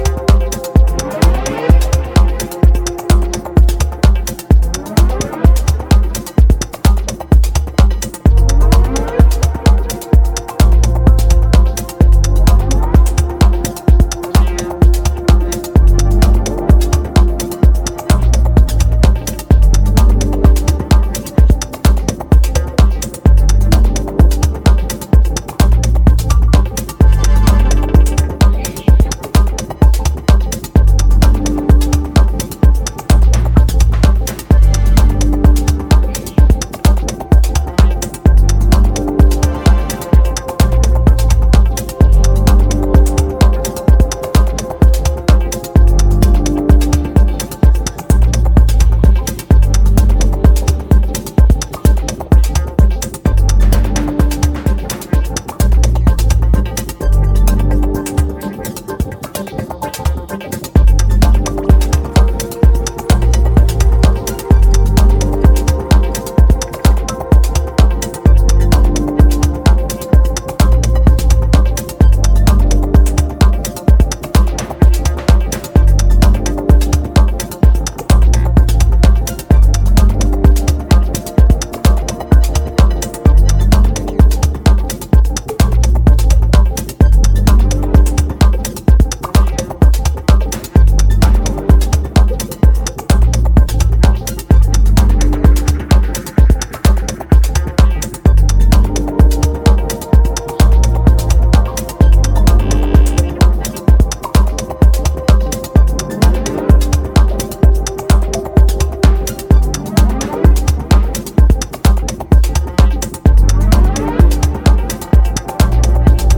blend House grooves with minimalistic elements